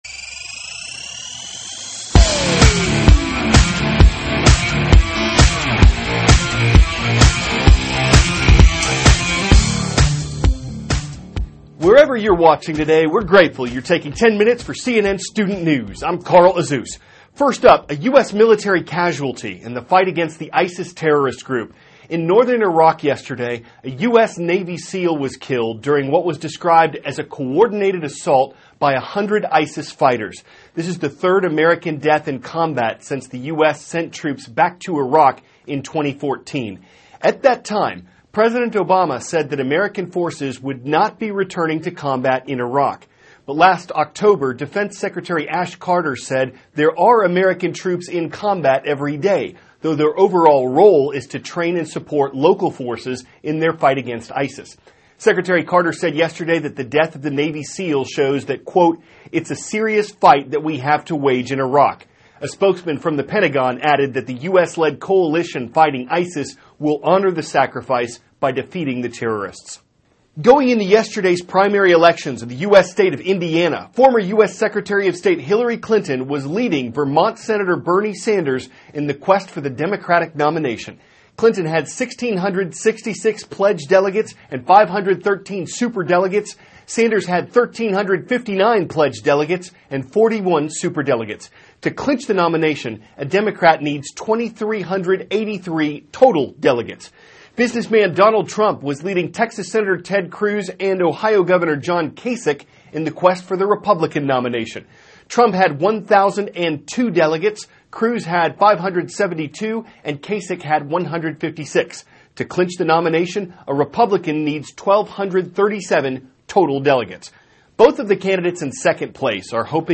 (CNN Student News) -- May 4, 2016 Navy SEAL Killed in Iraq; Hundred of Teachers Protest in Detroit; A Study Looks Into Mobile Addiction Among Teenagers. Aired 4-4:10a ET THIS IS A RUSH TRANSCRIPT.